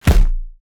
body_hit_large_44.wav